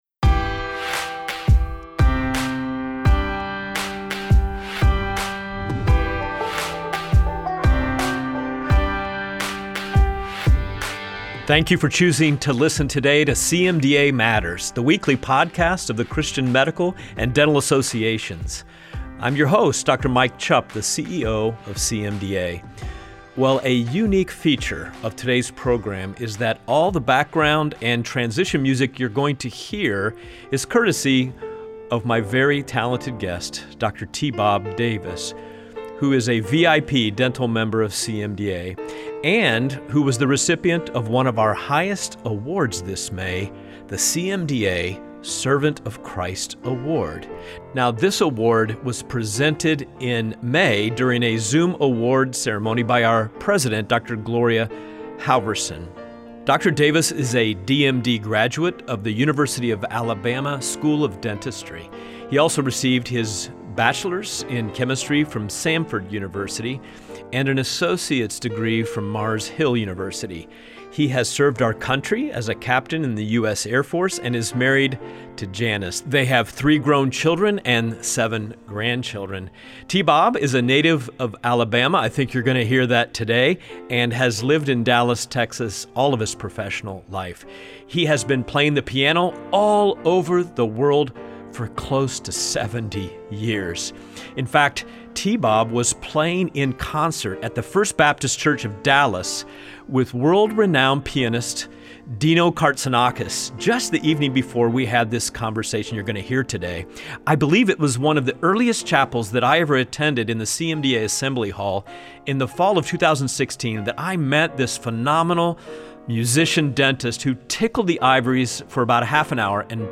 features one interview with brief news and announcements that matter to you